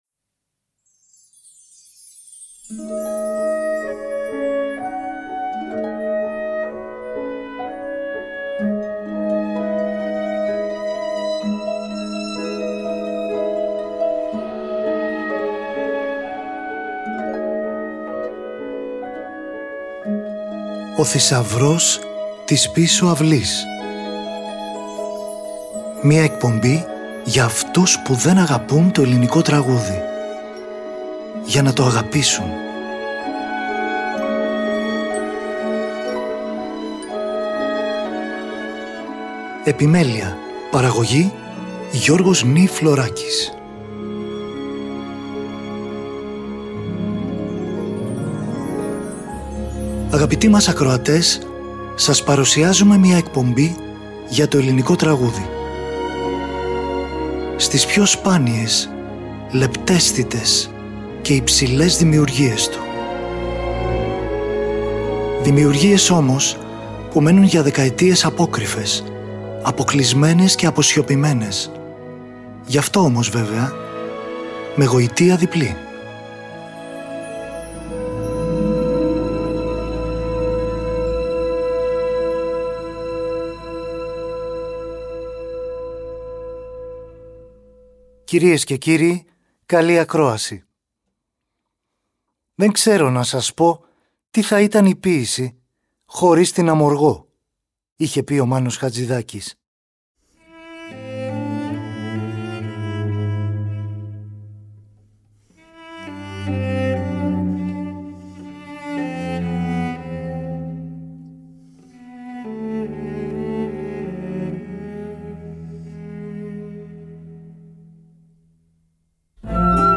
Καντάτα
για 3 φωνές, μικτή χορωδία και ορχήστρα